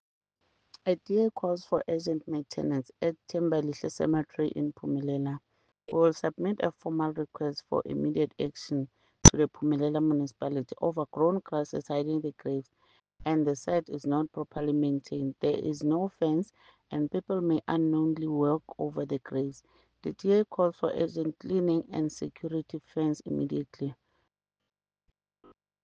English soundbite by Cllr Ntombi Mokoena, Afrikaans soundbite by Cllr Anelia Smit and